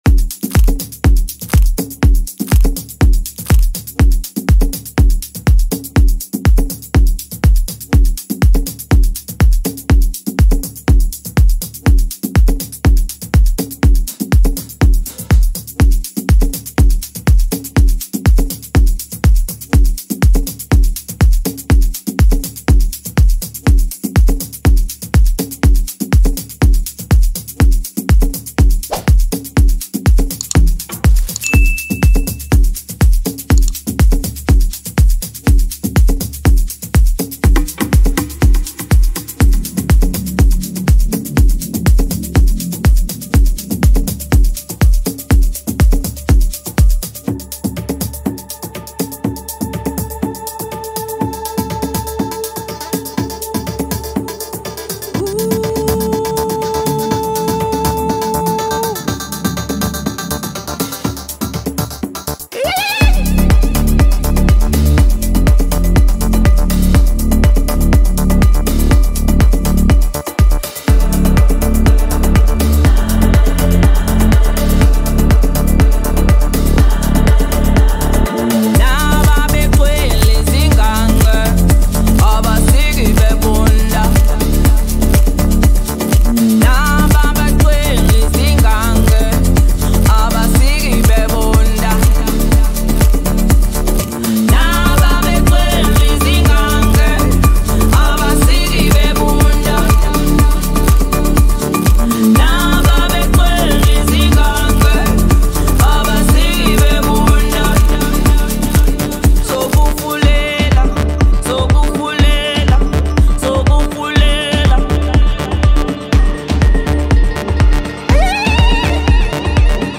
Talented South African Amapiano singer and songwriter